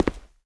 Index of /q3min/gamedata/sound/player/footsteps/
boot3.wav